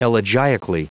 Prononciation du mot elegiacally en anglais (fichier audio)
Prononciation du mot : elegiacally